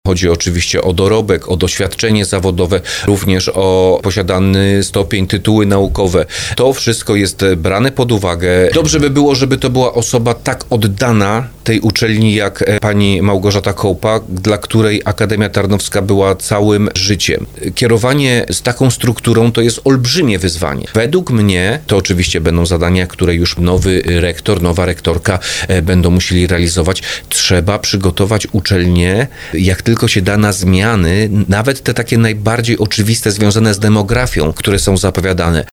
Jak mówił w audycji Słowo za Słowo